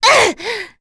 Cecilia-Vox_Damage_02.wav